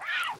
wash3.ogg